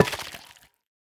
25w18a / assets / minecraft / sounds / block / sculk_catalyst / break8.ogg